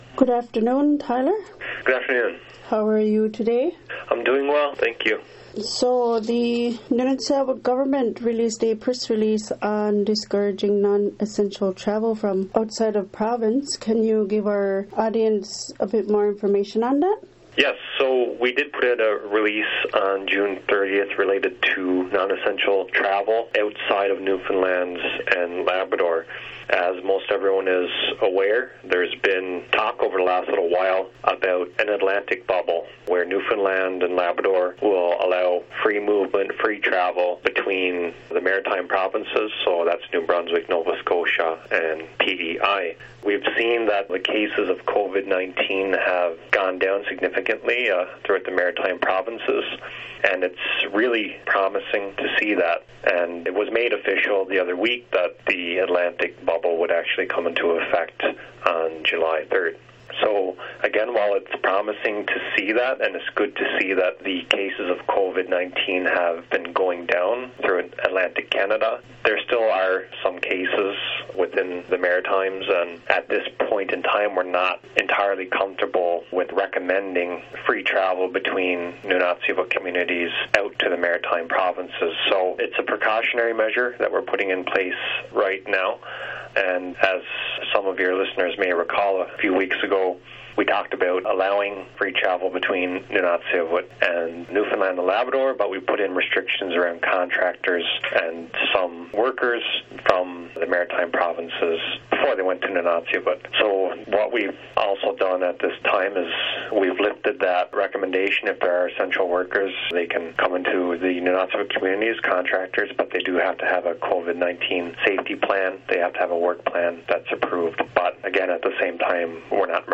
Tyler Edmunds is the First Minister for Nunatsiavut Government.